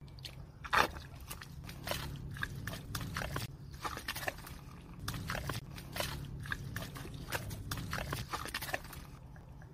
Tiếng Bước chân Chó lội trong Bùn sình lầy lội, Đất nhão…
Thể loại: Tiếng động
Description: Tiếng bước chân của chó lội qua khu vực đầy bùn sình, đất nhão tạo nên một hiệu ứng âm thanh đặc biệt sinh động và ấn tượng.
tieng-buoc-chan-cho-loi-trong-bun-sinh-lay-loi-dat-nhao-www_tiengdong_com.mp3